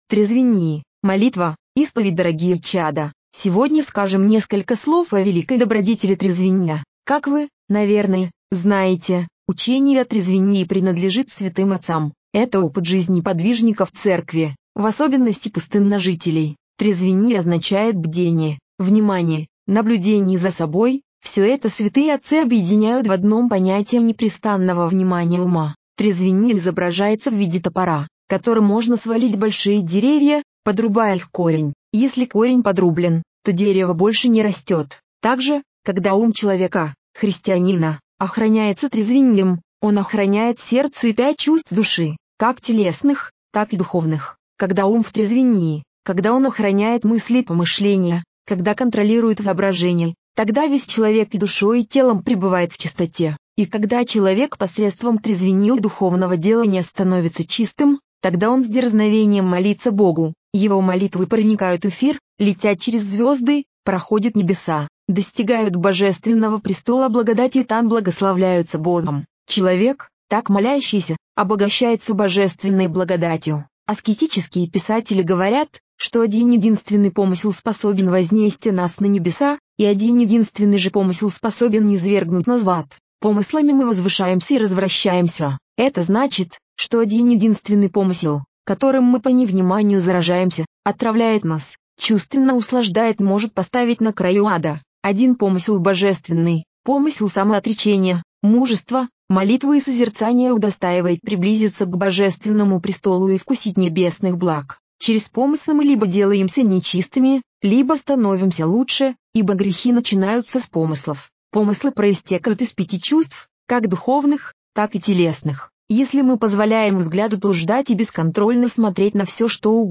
Беседа старца Ефрема в Америке в 1984 году.